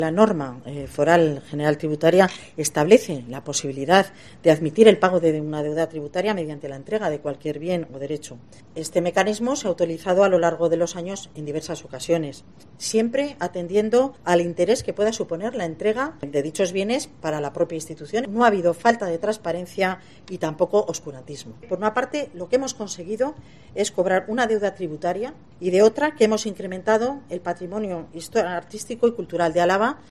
Itziar Gonzalo, diputada alavesa de Hacienda, explica que es posible la dación en pago